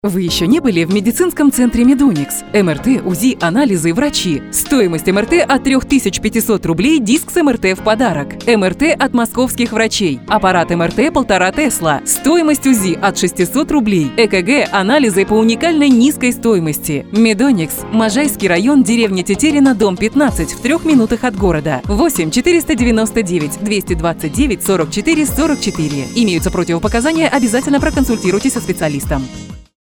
Жен, Рекламный ролик/Зрелый
Микрофон: RODE NT2-A, звуковая карта: Focusrite Scarlett 2Pre USB, профессиональная студия.